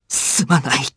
Fluss-Vox_Dead_jp.wav